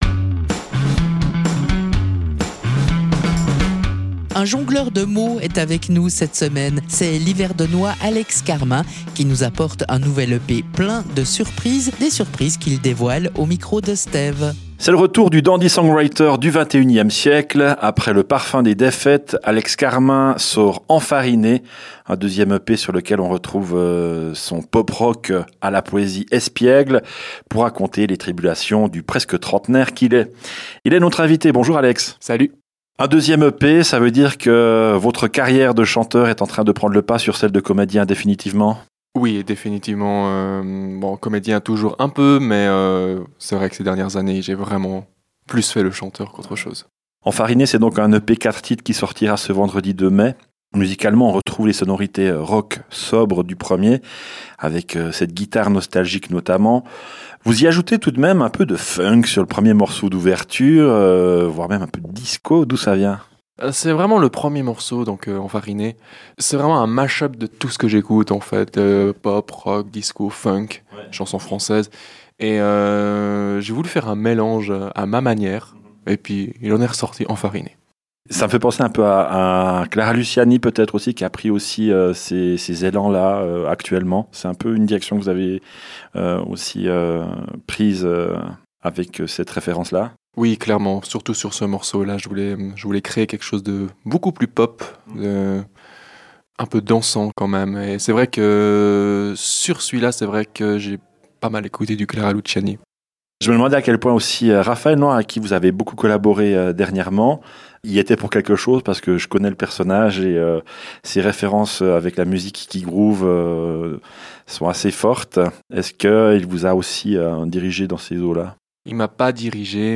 Rencontre avec cette "bonne pâte"! wink: wink: